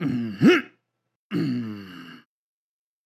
casting_fail.wav